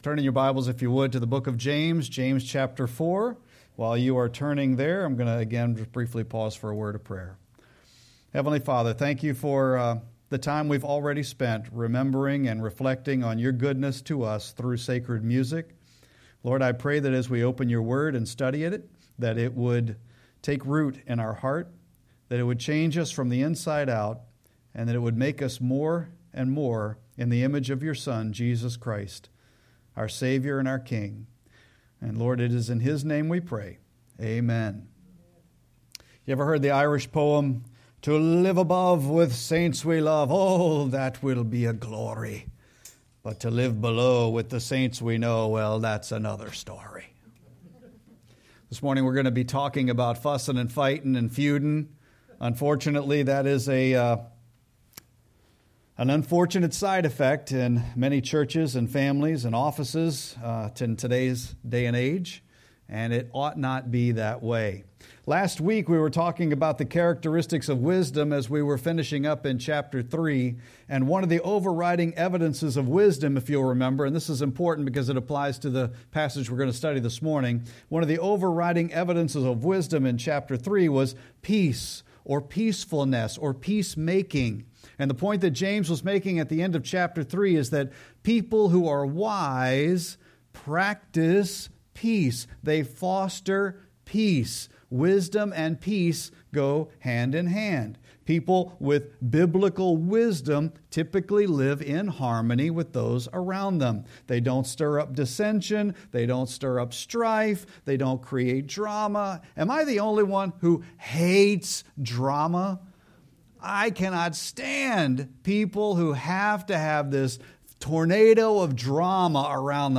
Sermon-8-17-25.mp3